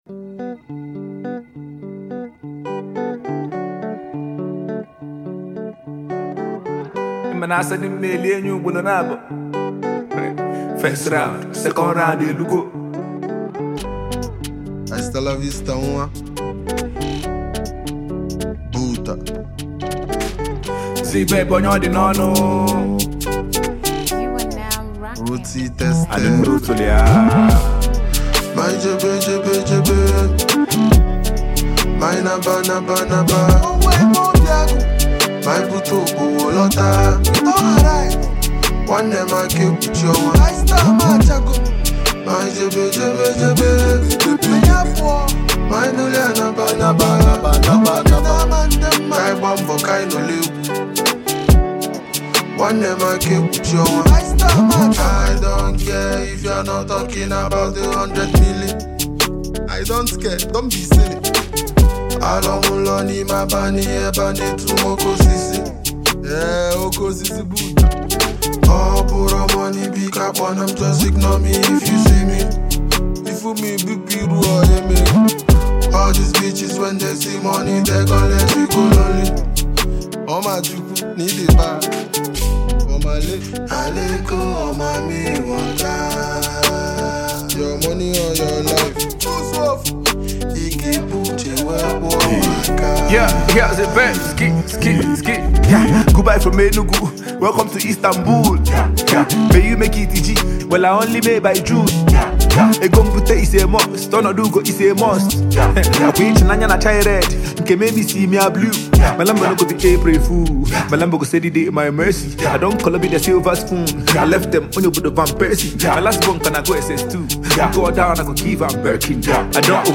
native hip-hop
lively music with real emotions